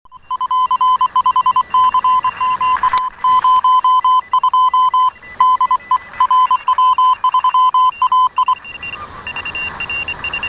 С удовольствием поработал в тесте "Партизанский радист", QRP/p.